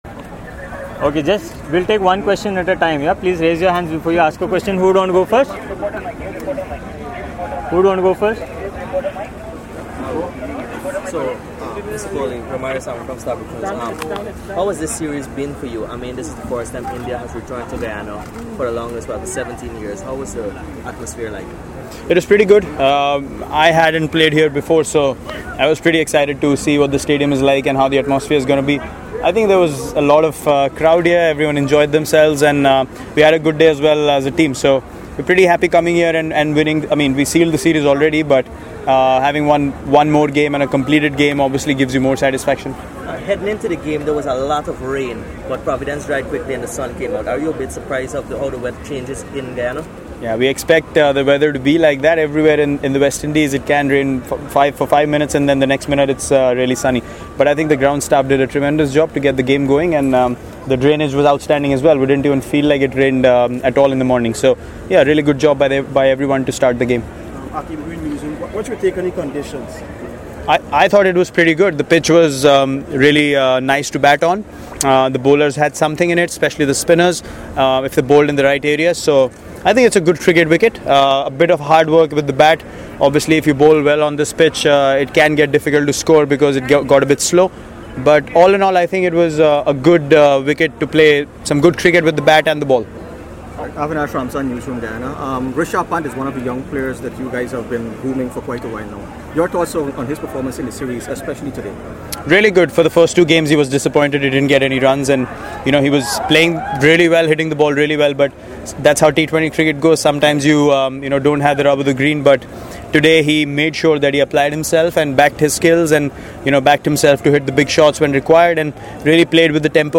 Virat Kohli, Captain, Indian Cricket Team. He spoke to the media in Guyana on Tuesday after the 3rd T20I against West Indies.